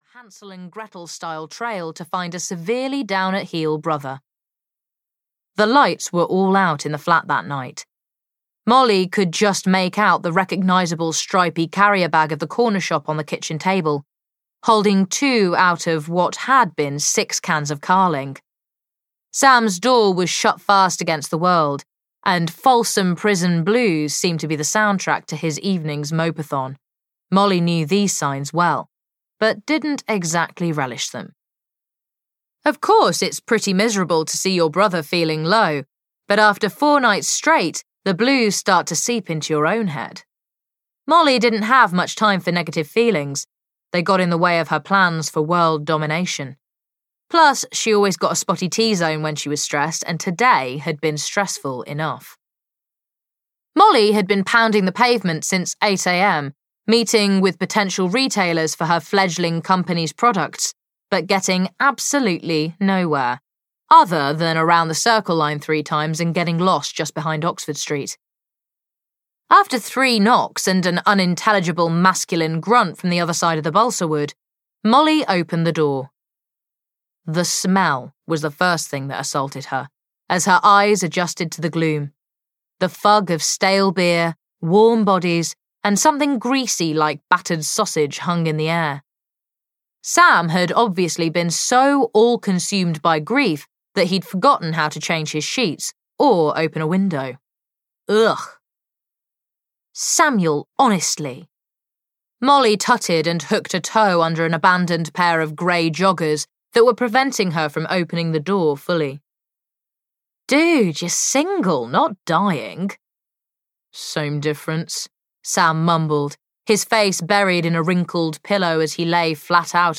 The Bad Boyfriends Bootcamp (EN) audiokniha
Ukázka z knihy